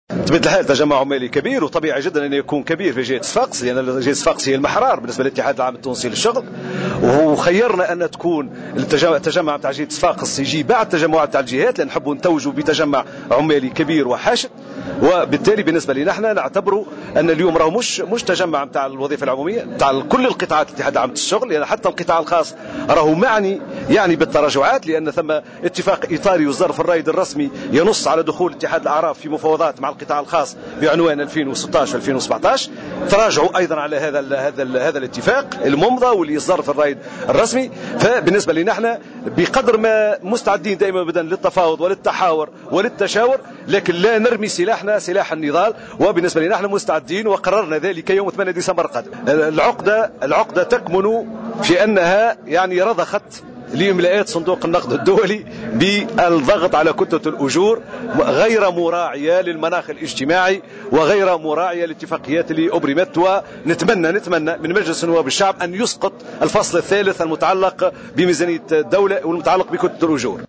على هامش تجمع عمالي نظمه الاتحاد في صفاقس اليوم الأحد